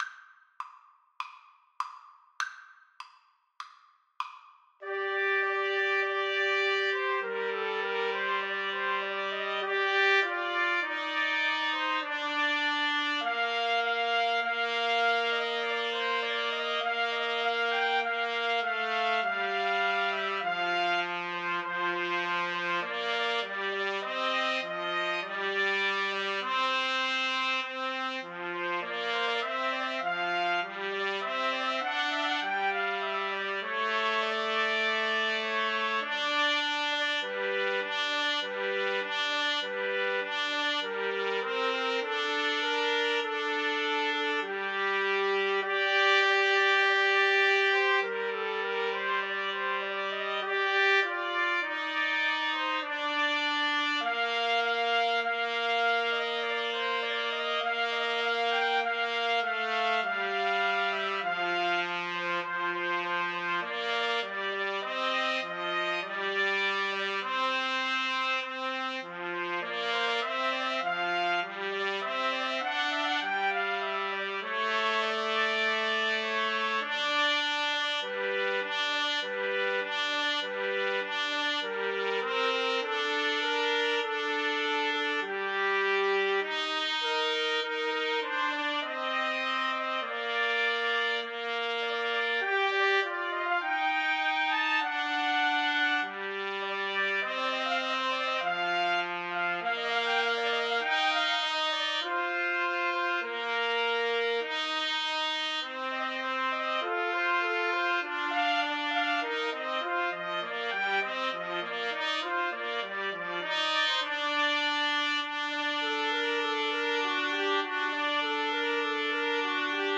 Free Sheet music for Trumpet Trio
Play (or use space bar on your keyboard) Pause Music Playalong - Player 1 Accompaniment Playalong - Player 3 Accompaniment reset tempo print settings full screen
Trumpet 1Trumpet 2Trumpet 3
Bb major (Sounding Pitch) C major (Trumpet in Bb) (View more Bb major Music for Trumpet Trio )
[Moderato]
4/4 (View more 4/4 Music)
Classical (View more Classical Trumpet Trio Music)